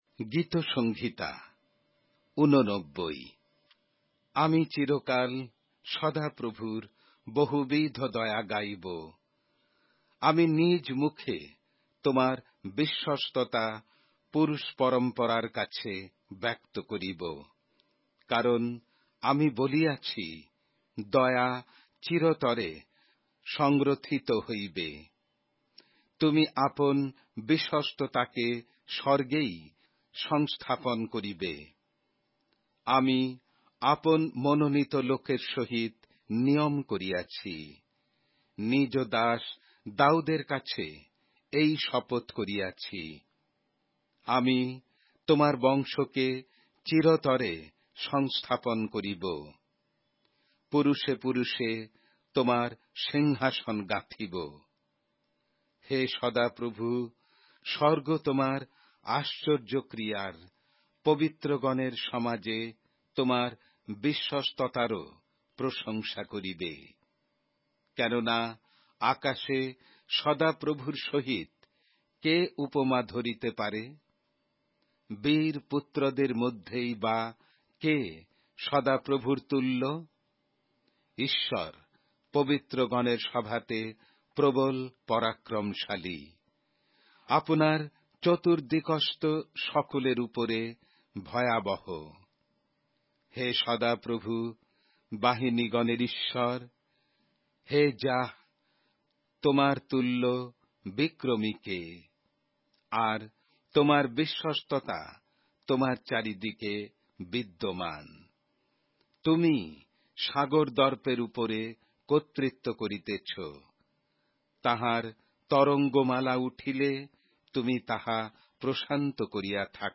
Bengali Audio Bible - Psalms 7 in Bnv bible version